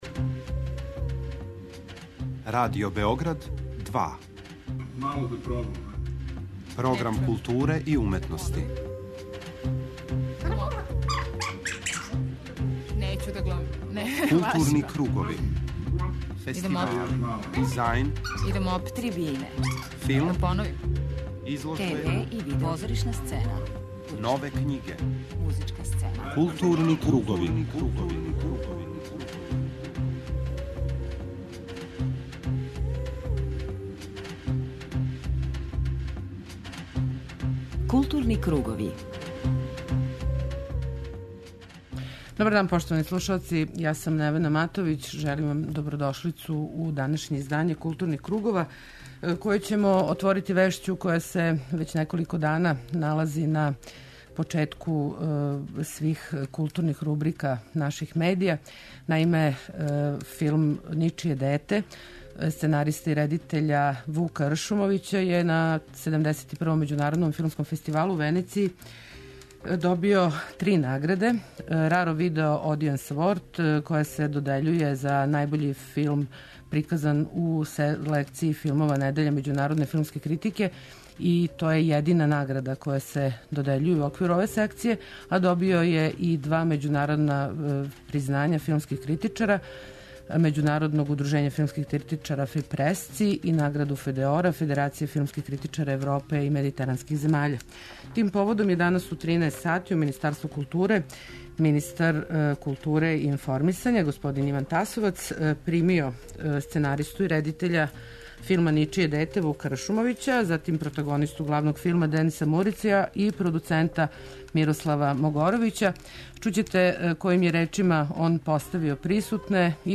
У данашњој емисији слушамо њега, али и друге учеснике фестивала и лауреате.